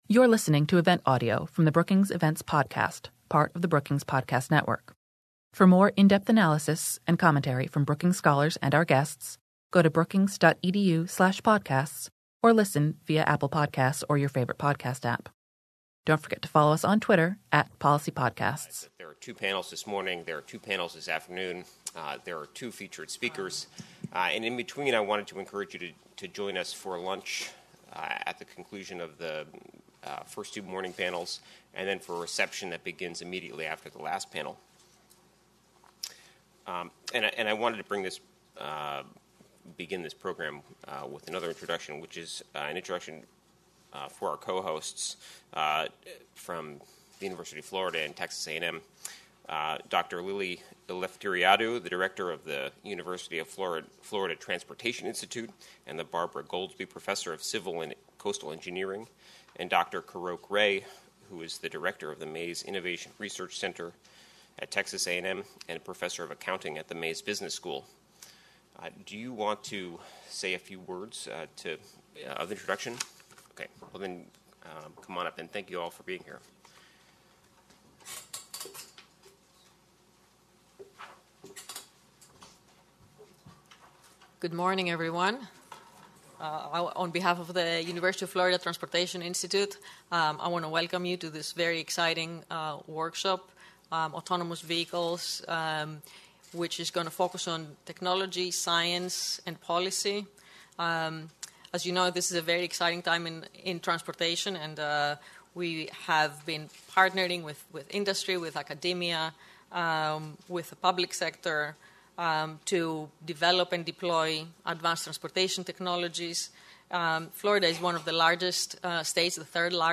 On July 25 the Brookings Institution hosted a full-day conference on how connecting vehicles to smart infrastructure will transform the future of transportation.
Morning Session
Engineers, researchers, economists, and government officials provided a realistic outlook on the current state of driverless cars.